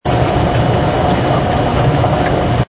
conveyor.wav